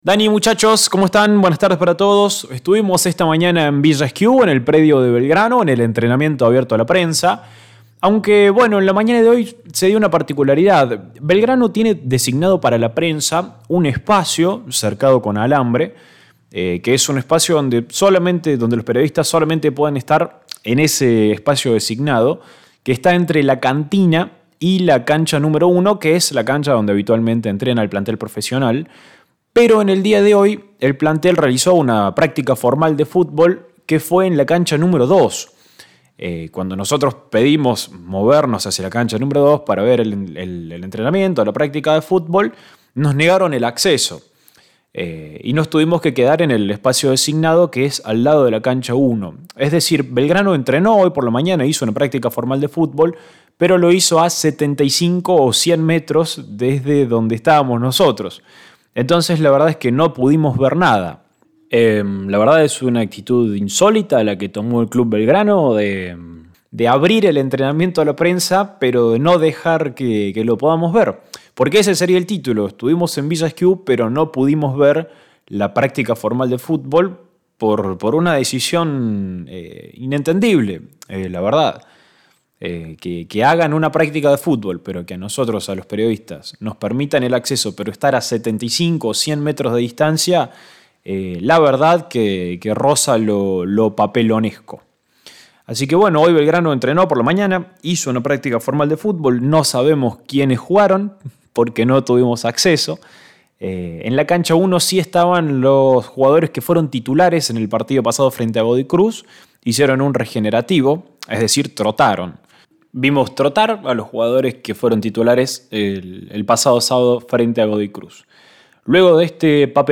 Ariel Rojas dialoga con los medios en el Predio Armando Pérez